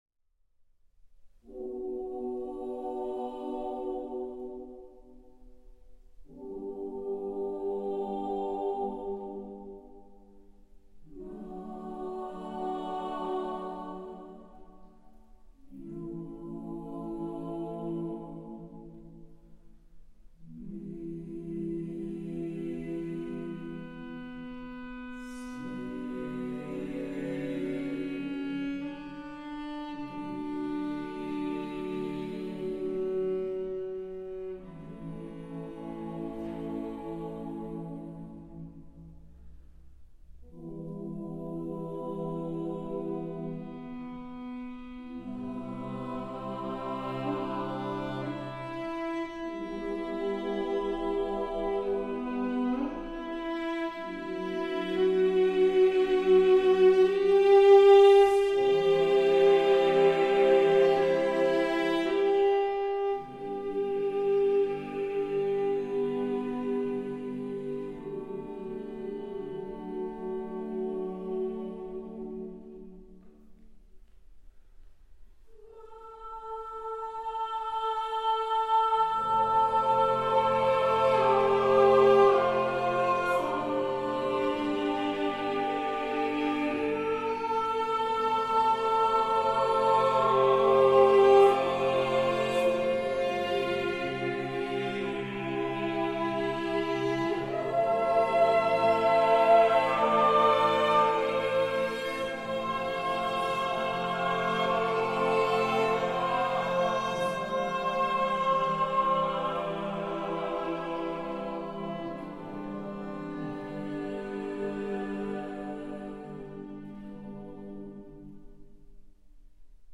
Cello und Chor – eine Traumkombination
Chor und Cello umarmen einander in dieser Musik.
Impressionen von der Aufnahme im Studio